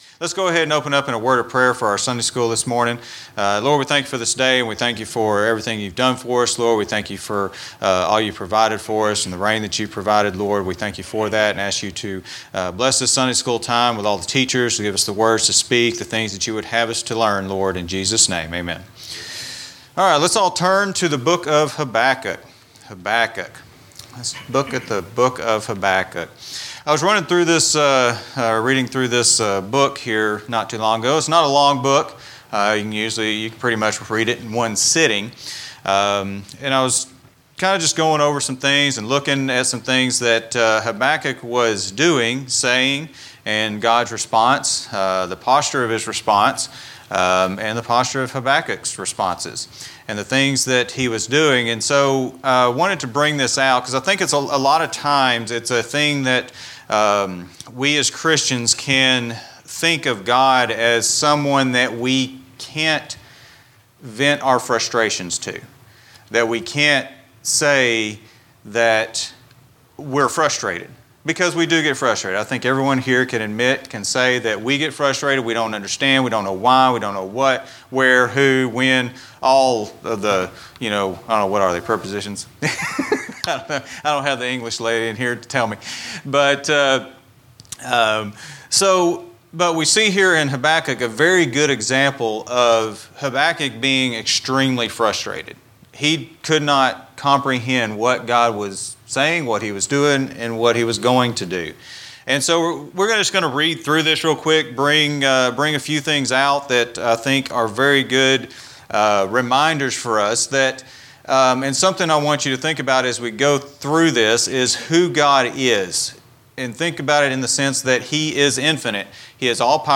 A message from the series "General Teaching." Should Christians bring their frustration and anger about God's action or inaction to God?